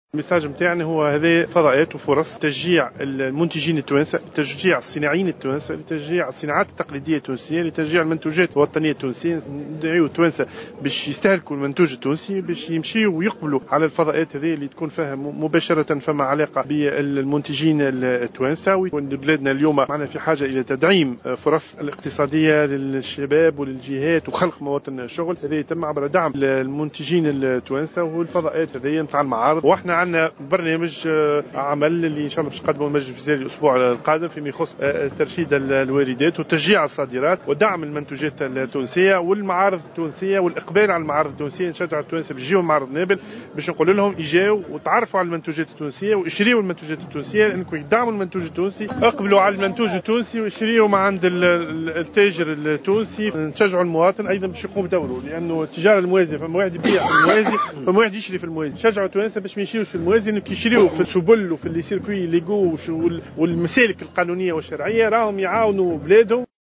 Dans une déclaration accordée à la correspondante de Jawhara FM, en marge de l'ouverture de la foire internationale de Nabeul, Ladhari a souligné la nécessité d'encourager les foires tunisiennes et de soutenir les producteurs et industriels tunisiens.